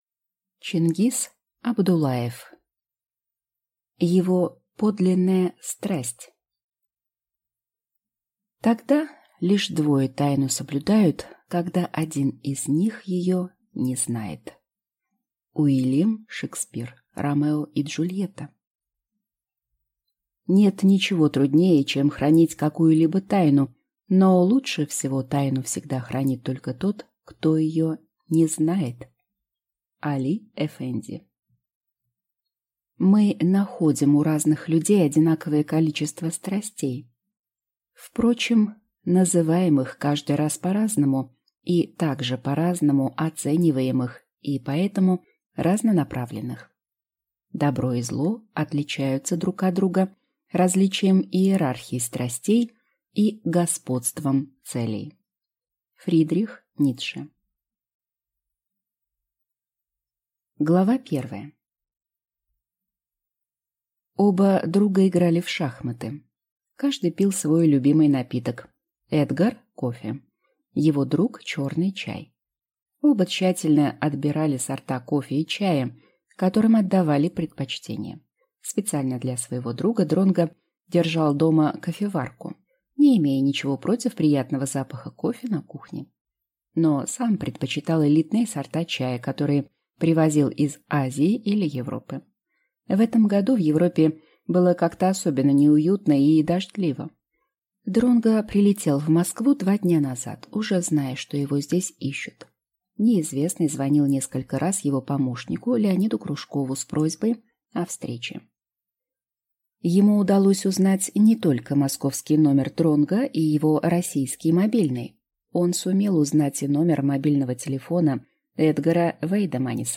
Ищу Золушку! (слушать аудиокнигу бесплатно) - автор Арина Теплова